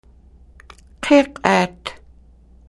Listen to the elder